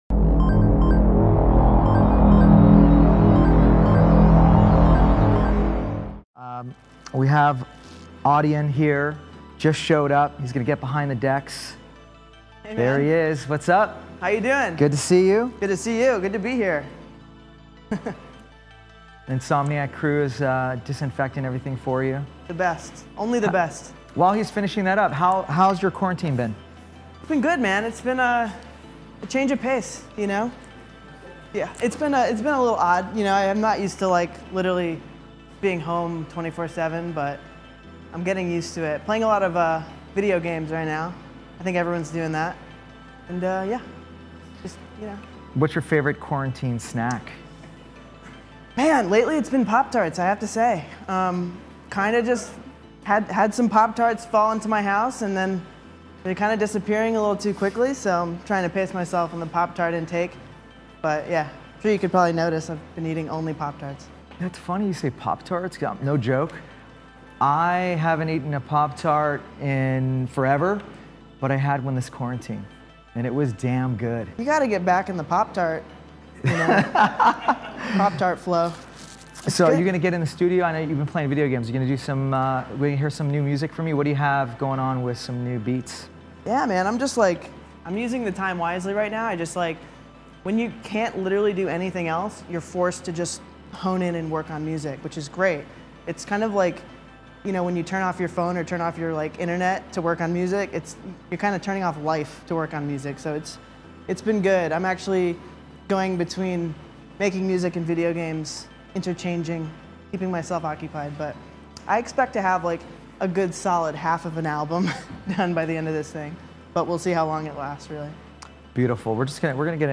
DJ Mixes and Radio
Genre: House